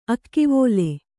♪ akkivōle